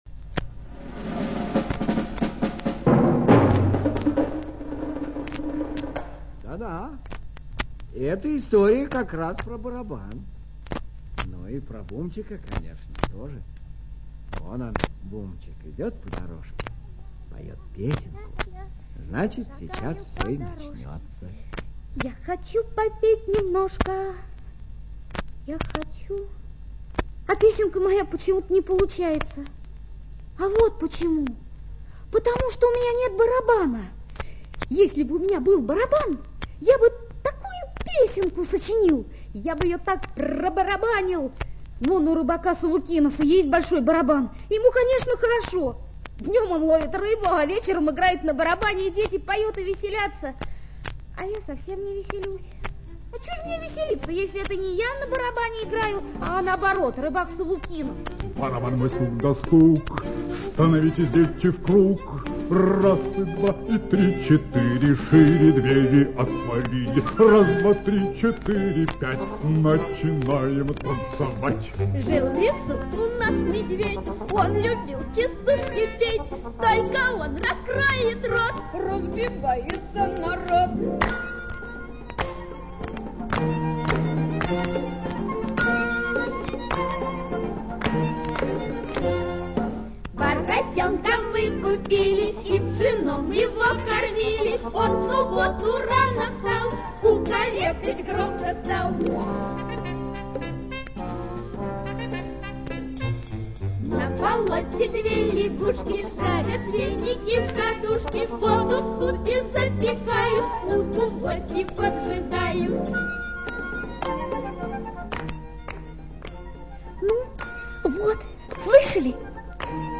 Бумчик и Барабан - аудиосказка Г.П. Корниловой
Как в любой сказке, так и в этой увлекательные приключения и песни.